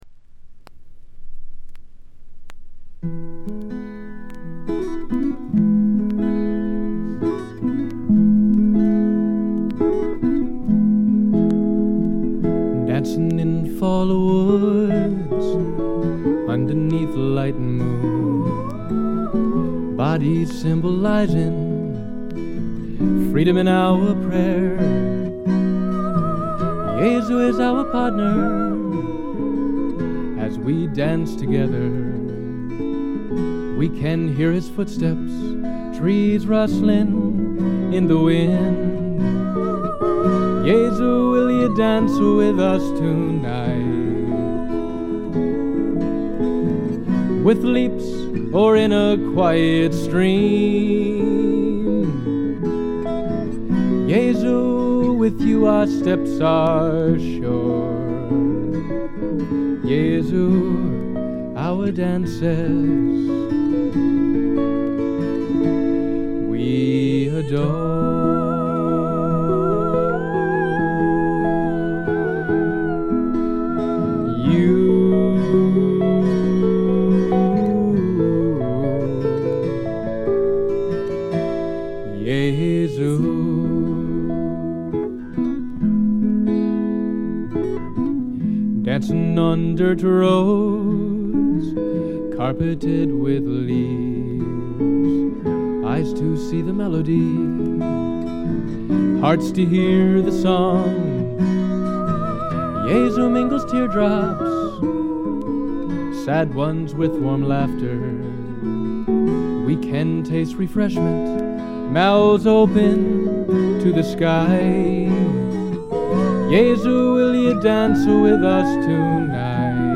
全編を通じて見事にサイレントで聖なる世界が展開します。
ずばりドリーミーフォークの名作と言って良いでしょう。
試聴曲は現品からの取り込み音源です。